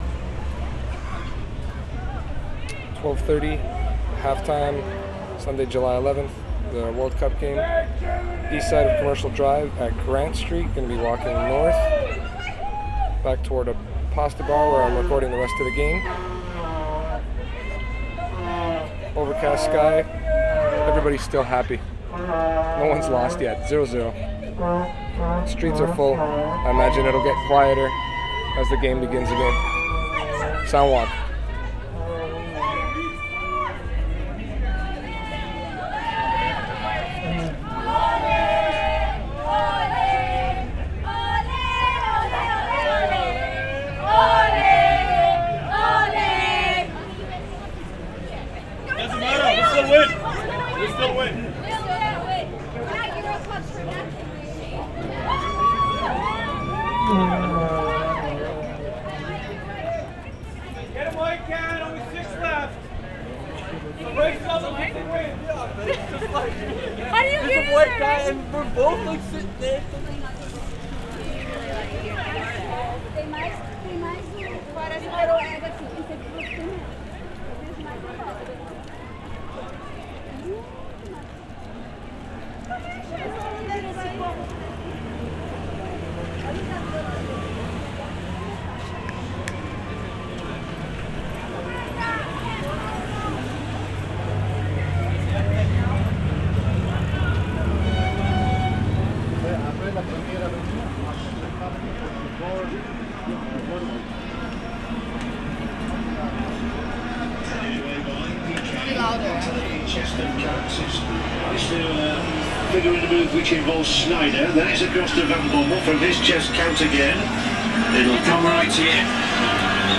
WORLD CUP ON COMMERCIAL DRIVE, JULY11/2010
Halftime Soundwalk, 4:48
1. Track ID @ intro, begin at Grant St walking North, Vuvuzelas at intro (!), chanting - Spanish supporters @ 0:40, crosswalk 'chirping' @ 1:15, air horn @ 1:39, in-game announcer @ 1:50 (passing a bar with an open window - the game just began and I missed the first minute of play), truck engine at 2:30, same announcer - different bar @ 3:05, horn/screaming at 3:35, vuvuzela @ 4:12.